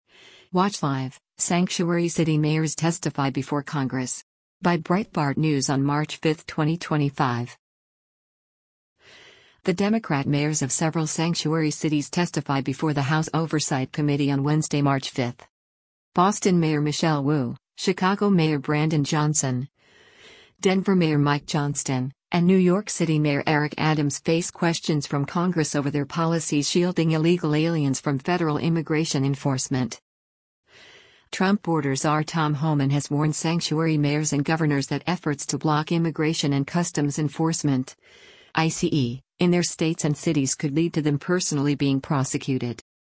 The Democrat mayors of several sanctuary cities testify before the House Oversight Committee on Wednesday, March 5.
Boston Mayor Michelle Wu, Chicago Mayor Brandon Johnson, Denver Mayor Mike Johnston, and New York City Mayor Eric Adams face questions from Congress over their policies shielding illegal aliens from federal immigration enforcement.